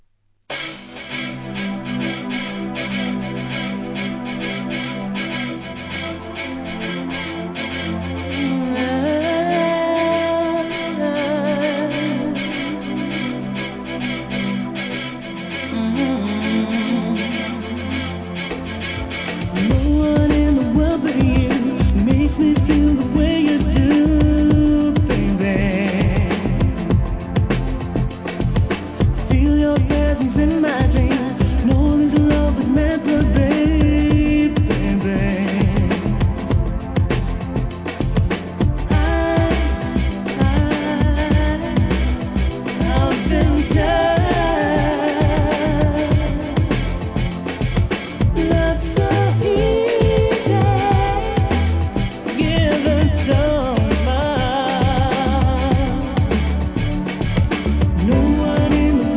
込み上げるグランドビート仕上げでこちらもオススメ!!